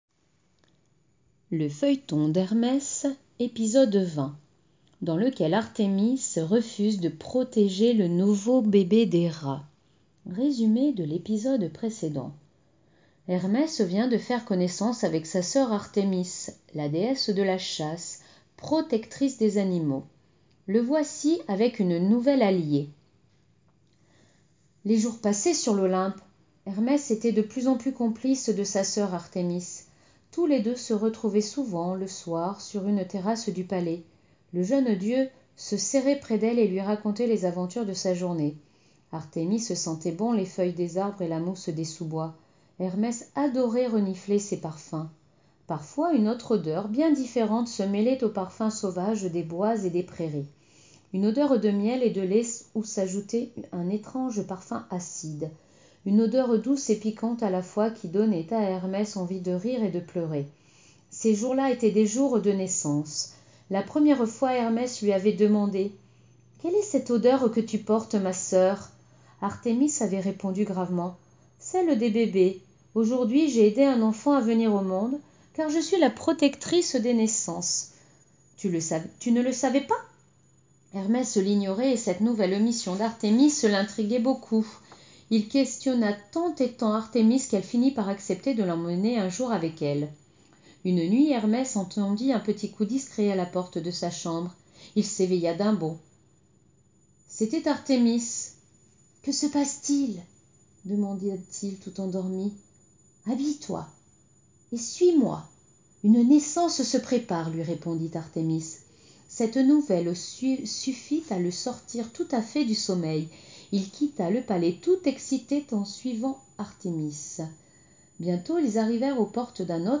Lecture de l'épisode 20 du Feuilleton d'Hermès.